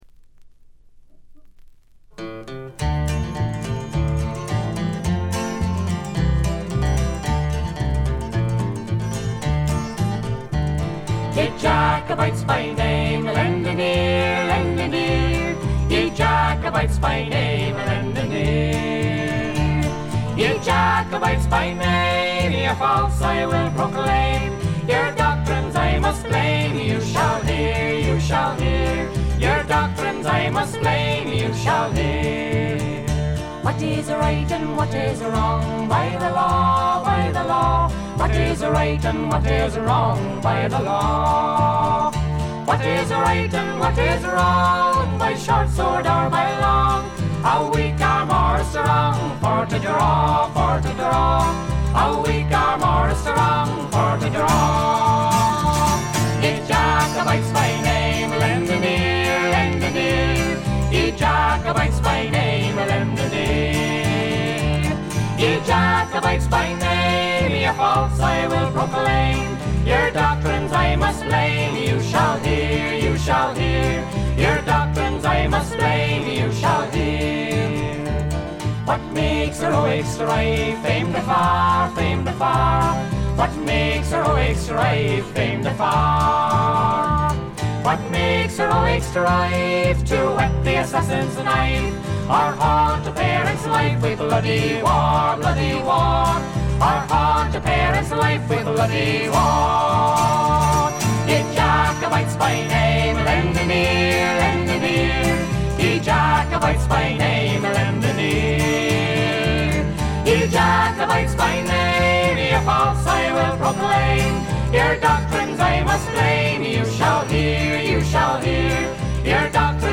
軽微なチリプチ程度。
モダン・フォークの香りただよう美しいコーラスワークが胸を打ちます。
アイリッシュトラッドの基本盤。
試聴曲は現品からの取り込み音源です。